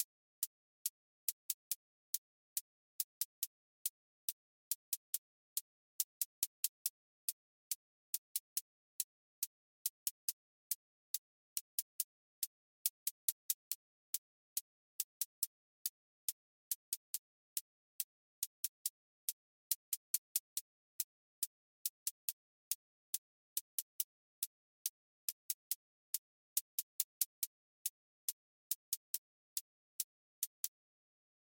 Trap 808 tension with clipped hats